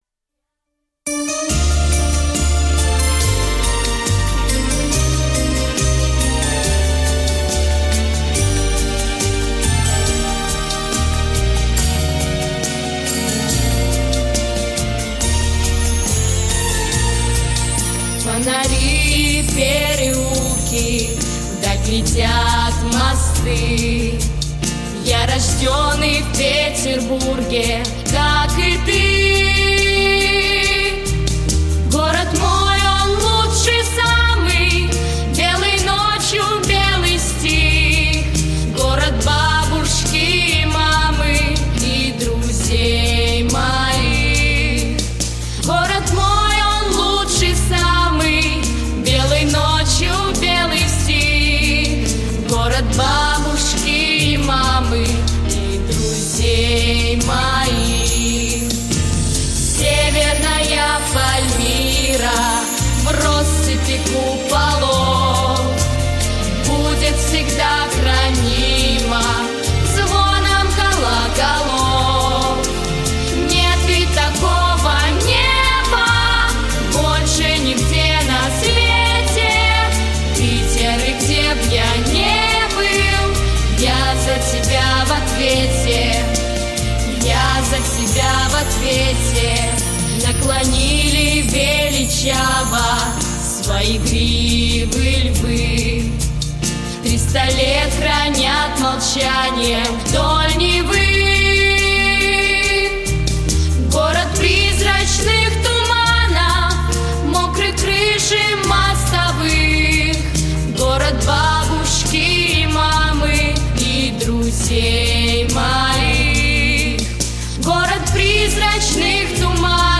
🎶 Детские песни / День рождения 🎂